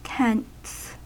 Ääntäminen
Ääntäminen US Haettu sana löytyi näillä lähdekielillä: englanti Käännöksiä ei löytynyt valitulle kohdekielelle. Tents on sanan tent monikko.